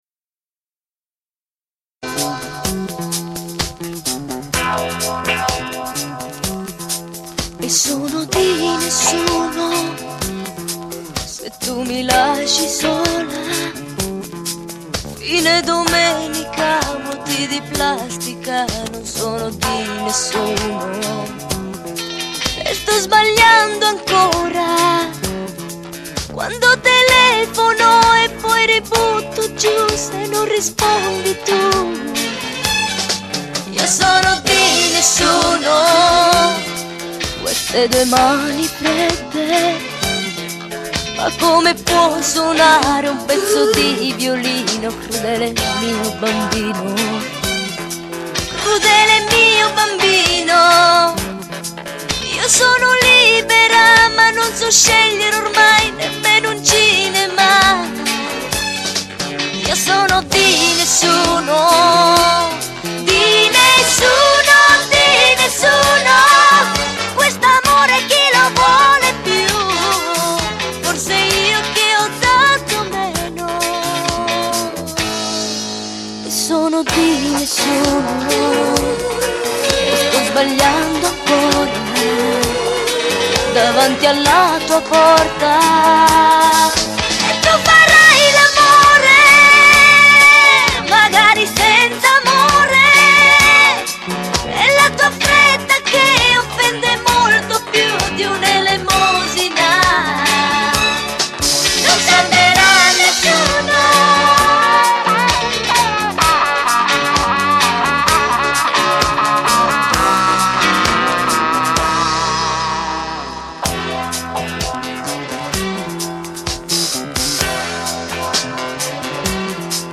Basso
Batteria
Chitarra Acustica
Tastiere